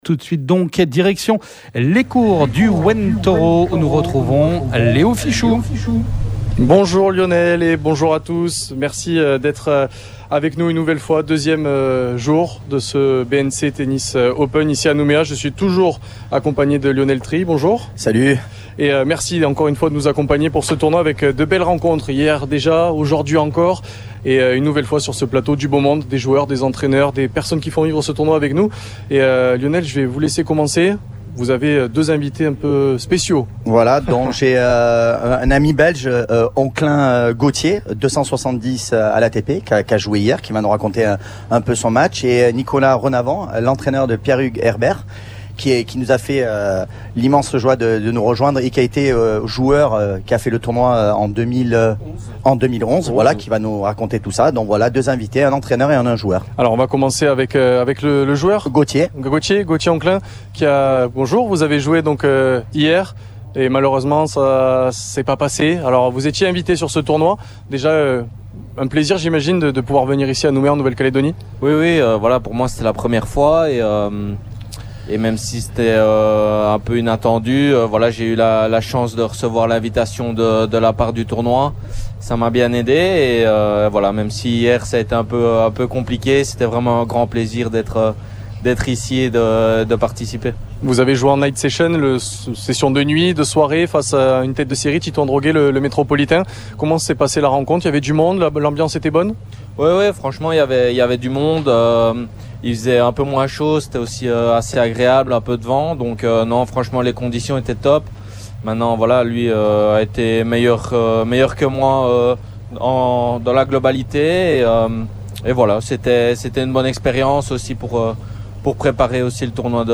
Le BNC Tennis Open 2026 sur les courts du Ouen Toro.